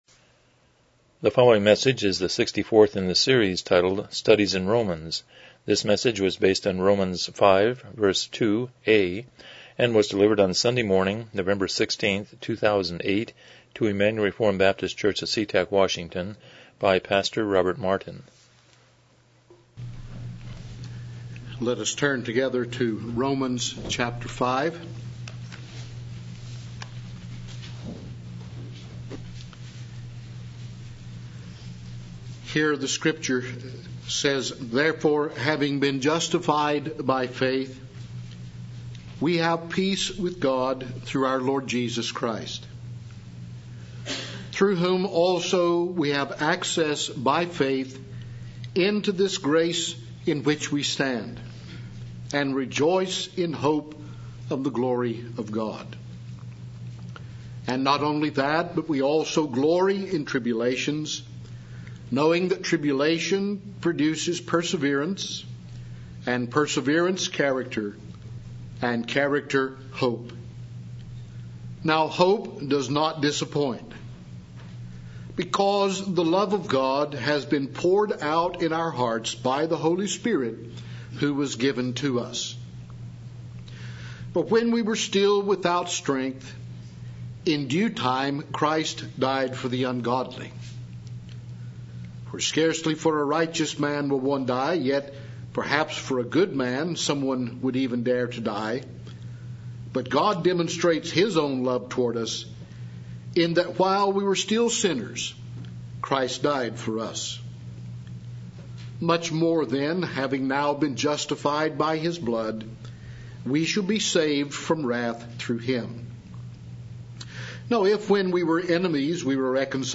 Romans 5:2 Service Type: Morning Worship « 12 The Uniqueness of Man